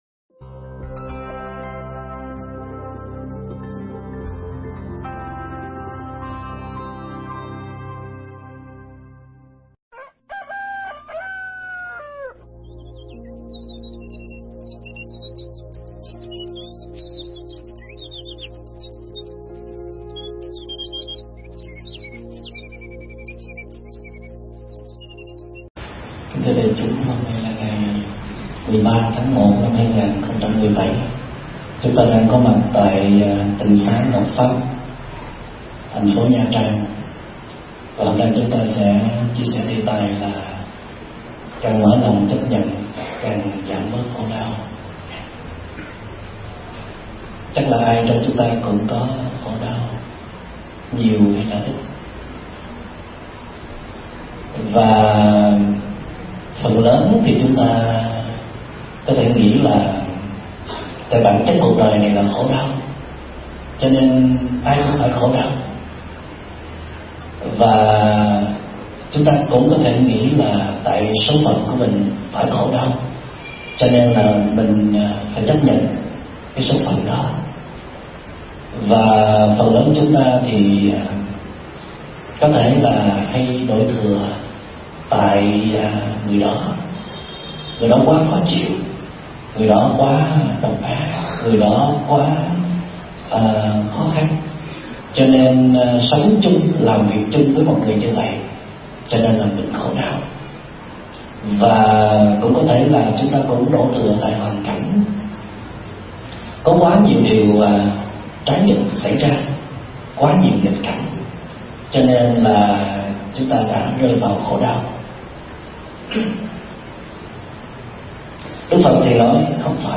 Mp3 Pháp Thoại Càng mở lòng chấp nhận càng giảm bớt khổ đau - Đại Đức Thích Minh Niệm thuyết giảng tại Tịnh xá Ngọc Pháp, ngày 13 tháng 1 năm 2017